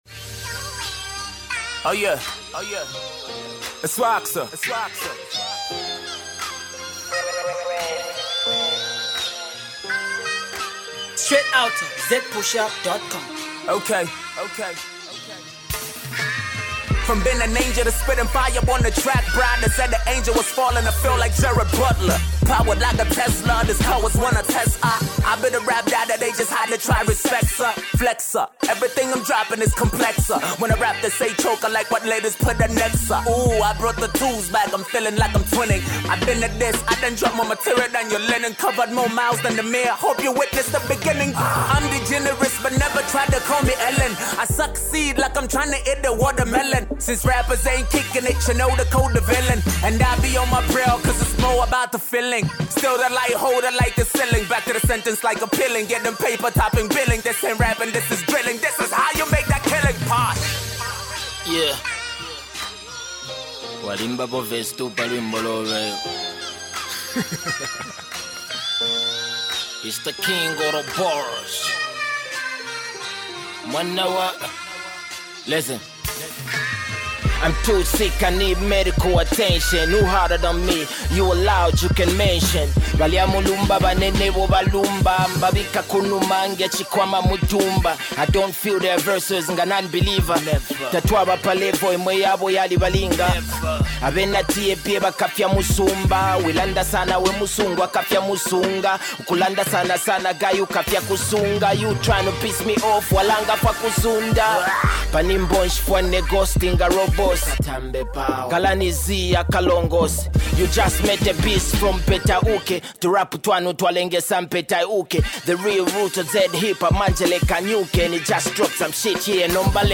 Brand new dance-hall banger